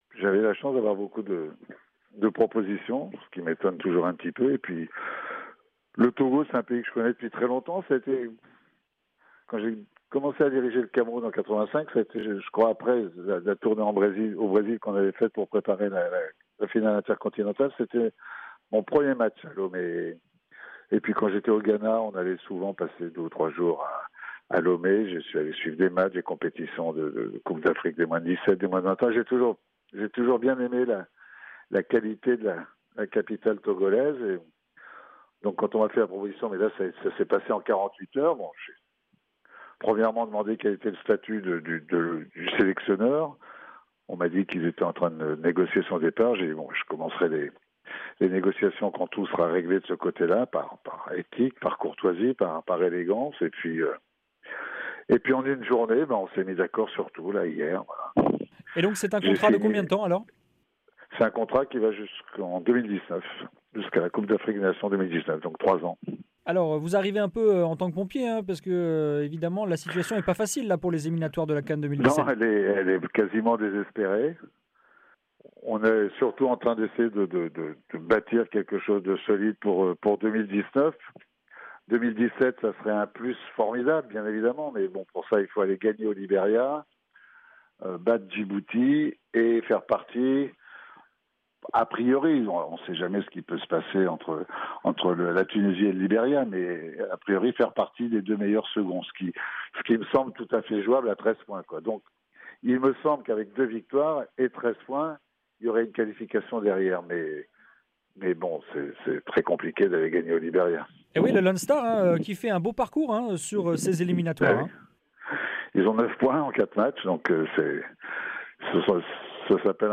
Si la qualification pour la CAN 2017 semble compromise, Leroy espère pouvoir bâtir une équipe solide capable de se qualifier pour l’édition 2019 au Cameroun. Entretien